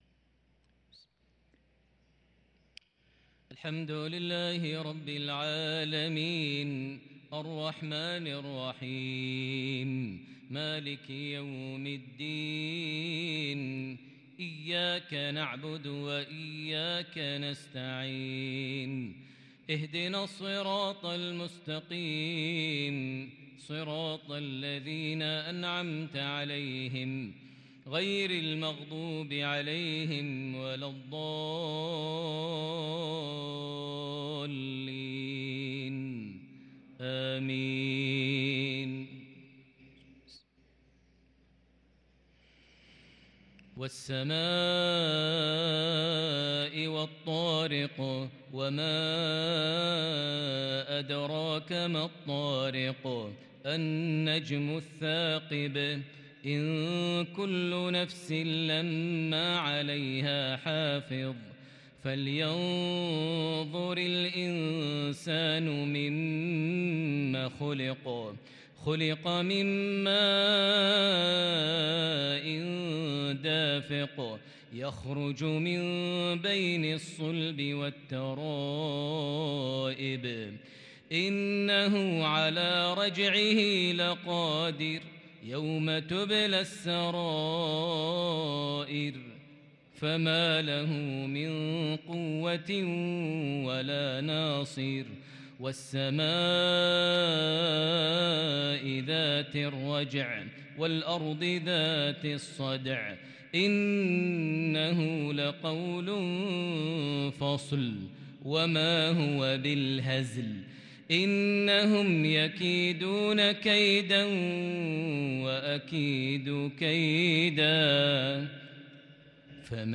صلاة المغرب للقارئ ماهر المعيقلي 26 جمادي الأول 1444 هـ
تِلَاوَات الْحَرَمَيْن .